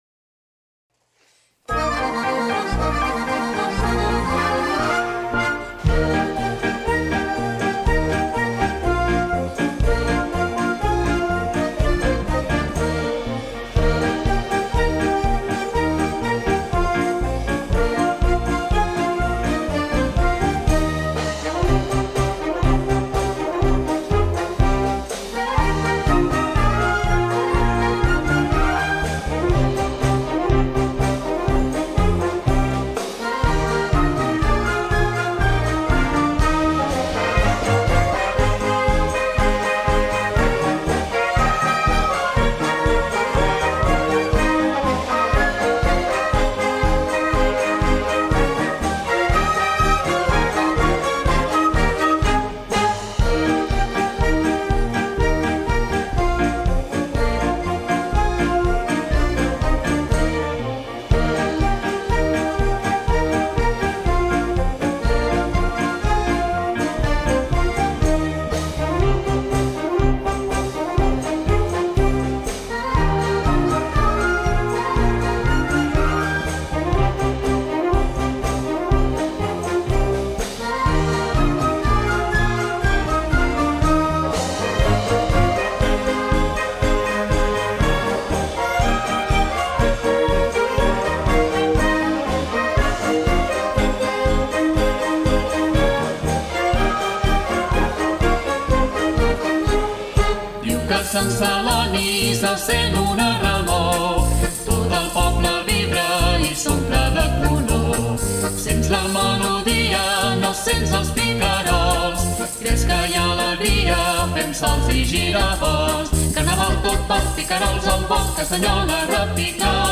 Polca_de_Sant_Celoni_Cantada.mp3